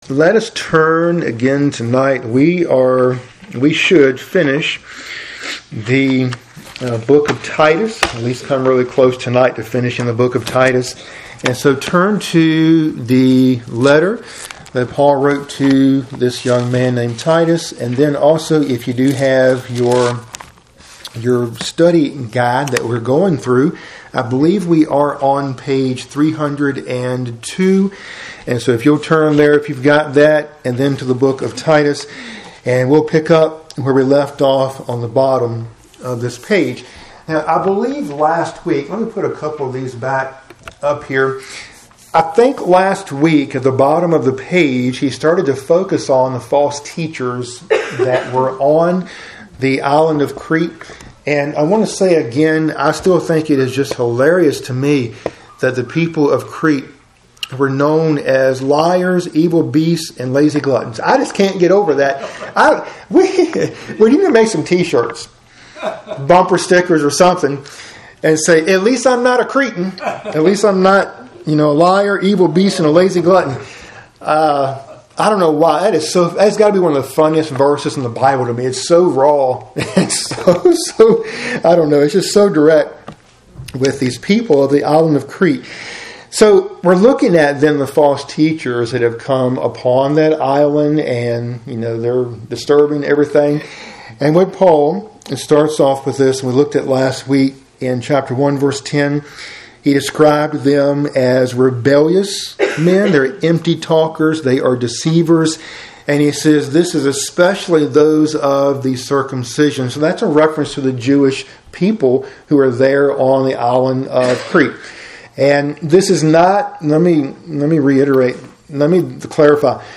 Midweek Bible Study – Lesson 61 (cont.)